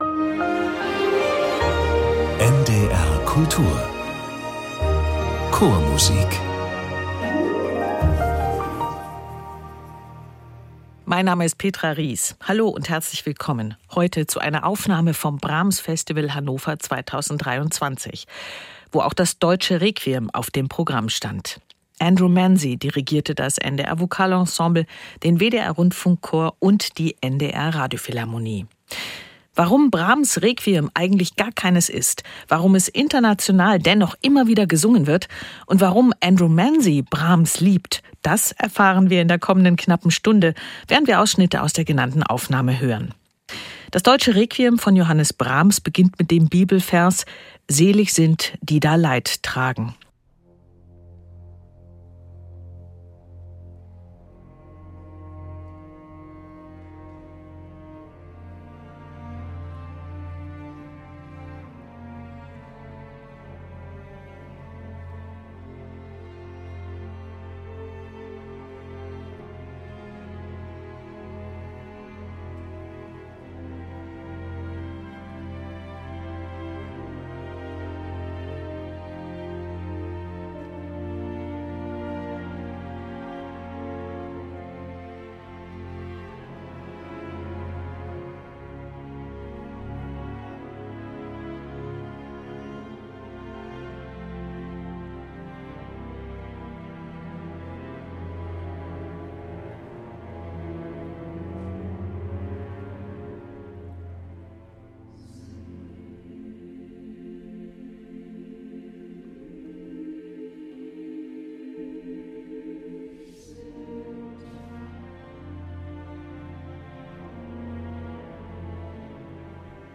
Das NDR Vokalensemble, der WDR Rundfunkchor und die NDR Radiophilharmonie beim Brahms Festival Hannover.